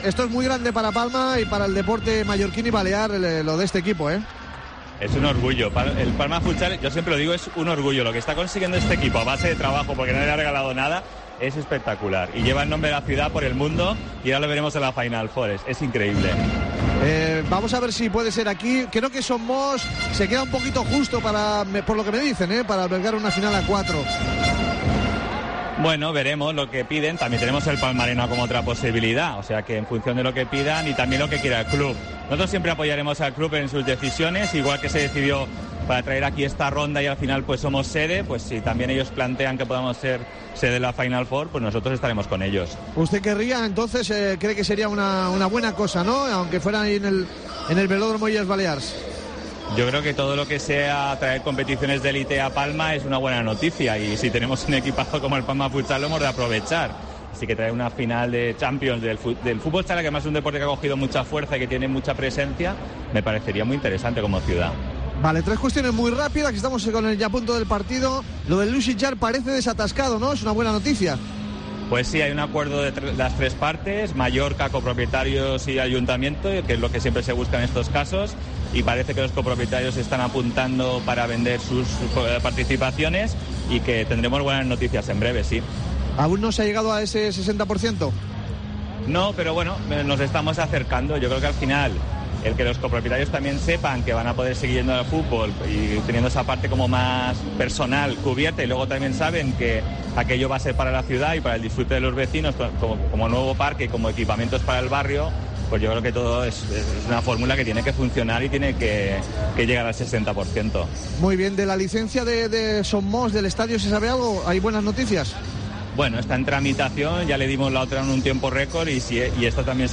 Hablamos con el alcalde de Palma José Hila en Tiempo de Juego Liga de Campeones futsal. El alcalde se muestra orgulloso de la clasificación del Palma Futsal para la final 4, además preguntamos por el caso Sitjar, la licencia de obra de Son Moix y el caso La Salle.